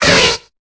Cri de Munja dans Pokémon Épée et Bouclier.